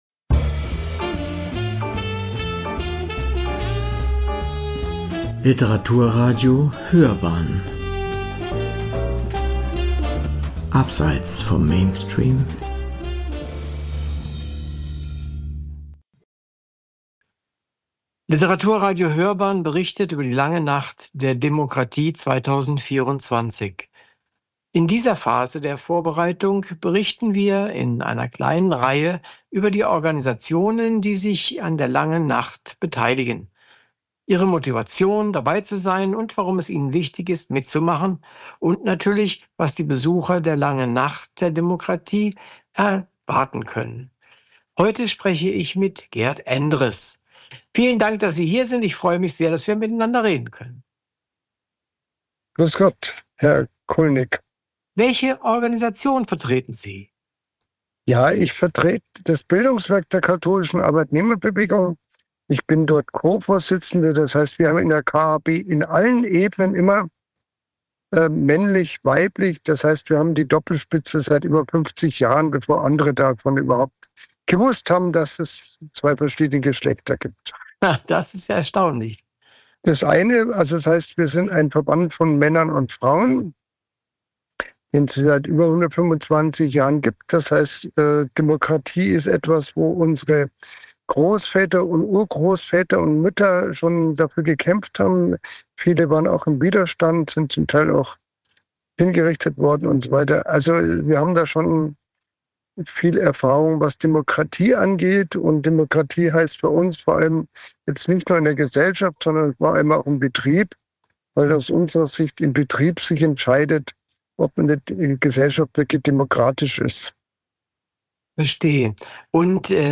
LNdD24 – Bildungswerk der kath. Arbeitnehmerbewegung – Interview